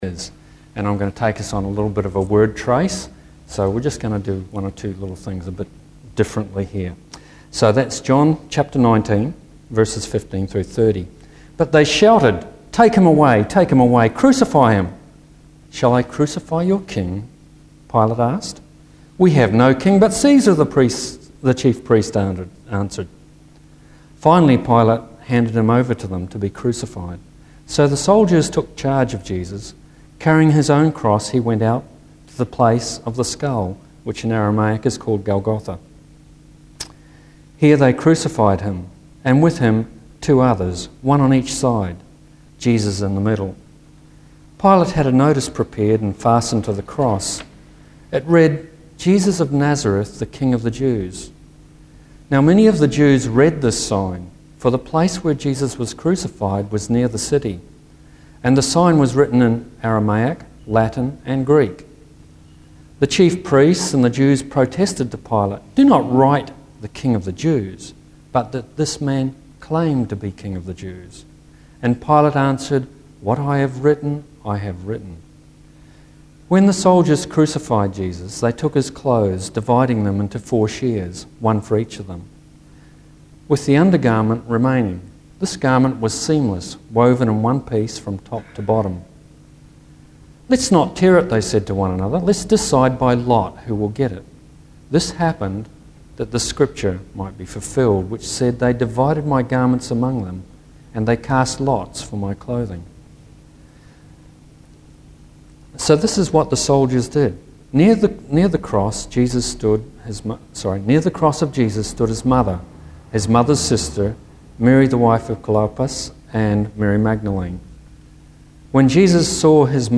Easter Gospel Message